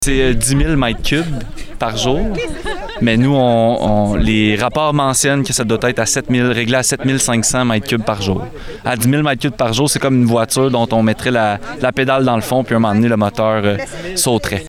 Mardi, a Ville a convié les médias pour faire part des projections à venir pour son usine de traitement de l’eau potable.